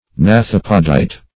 Search Result for " gnathopodite" : The Collaborative International Dictionary of English v.0.48: Gnathopodite \Gna*thop"o*dite\, n. (Zool,) Any leglike appendage of a crustacean, when modified wholly, or in part, to serve as a jaw, esp. one of the maxillipeds.